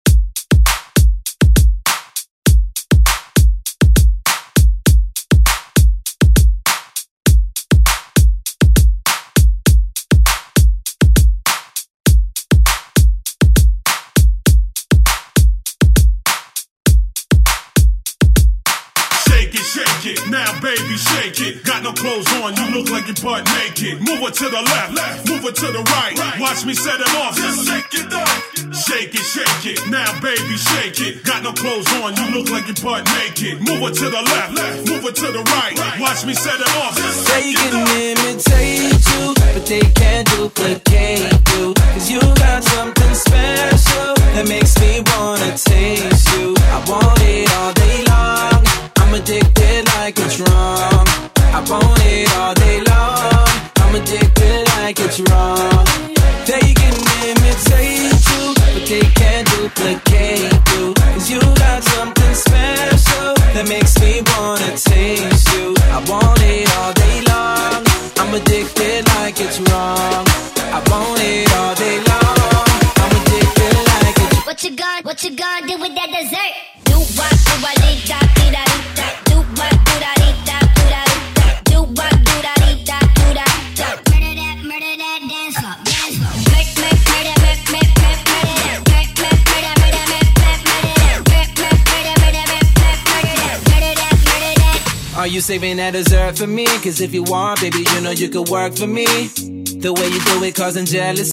Genres: CHRISTMAS , DANCE , RE-DRUM
Clean BPM: 135 Time